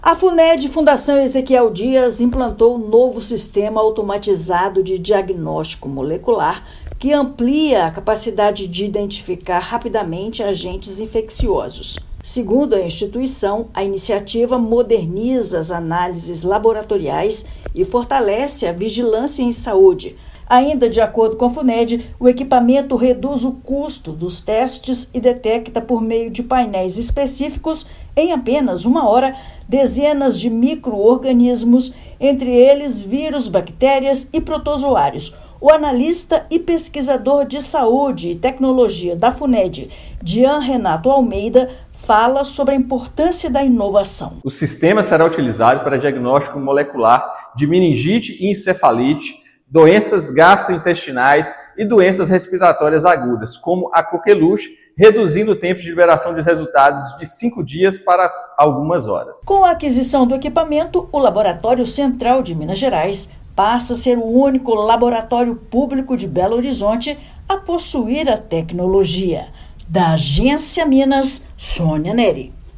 Em alguns casos, espera pelo resultado cai de cinco dias para apenas uma hora. Ouça matéria de rádio.